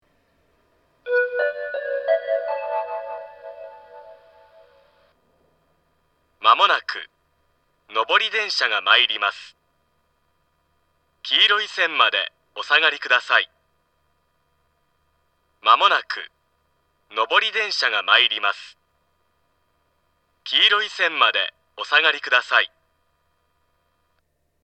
自動放送
仙石型（男性）
仙石型男性の接近放送です。